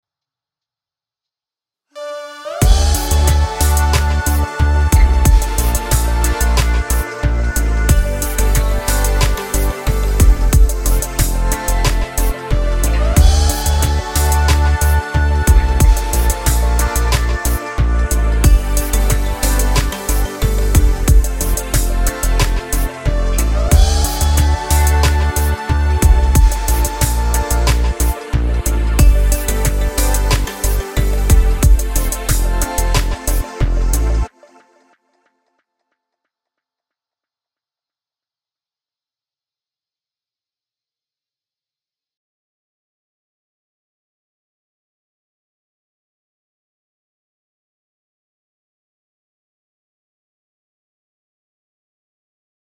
Here's an original example to highlight the difference; this electronically produced progression goes D - Bb - G - F, and you can hear the bass play each of those root notes as each chord is voiced:
Progression With Changing Bass Notes